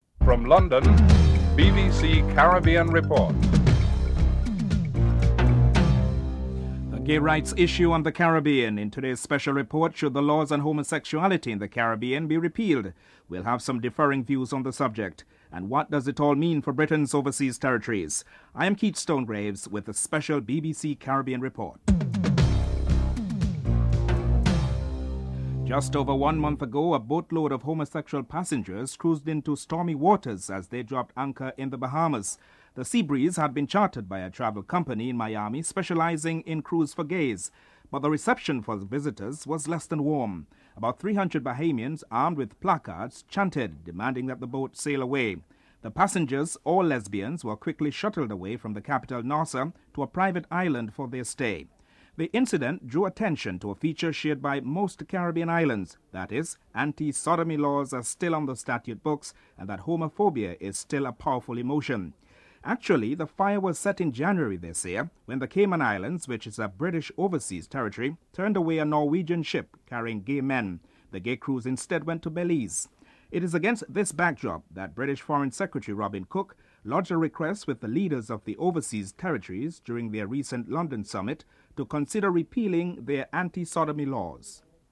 1. Special report on the gay rights issues in the Caribbean and what it all means for British Overseas Territories (00:00-00:25)